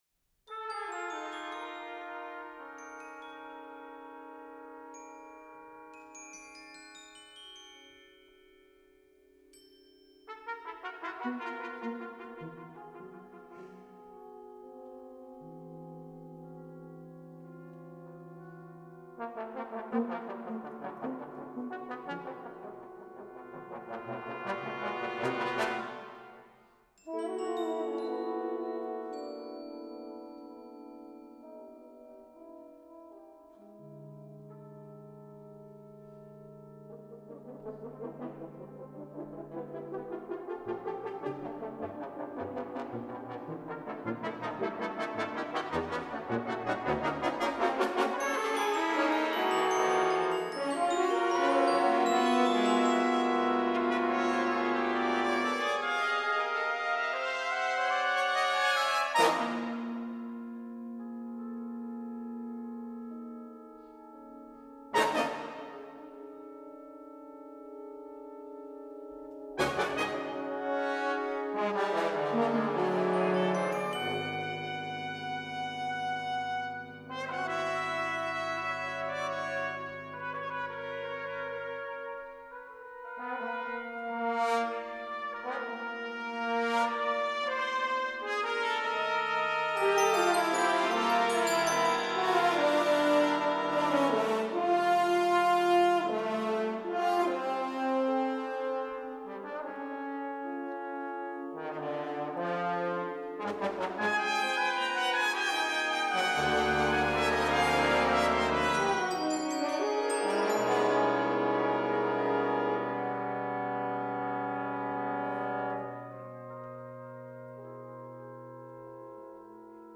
Excerpt of live performance in 2009
Composer of contemporary classical music